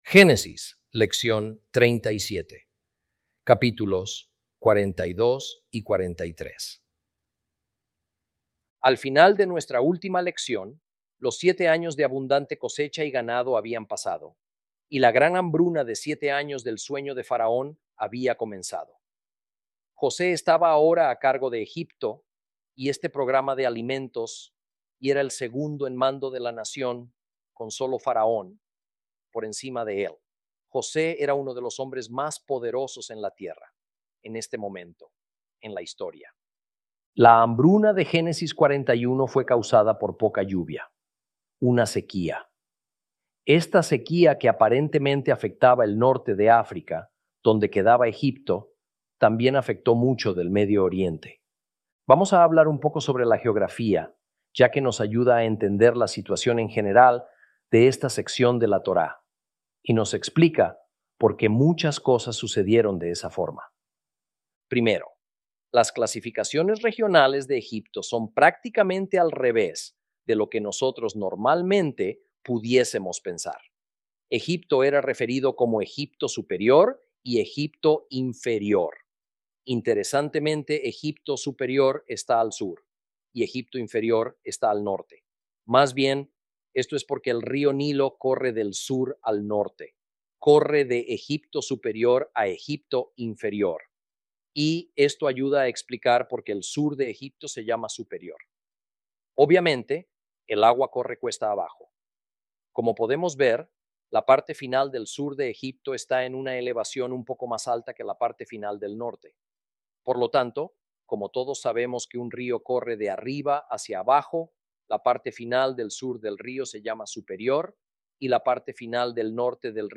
Lección 37 – Génesis 42 & 43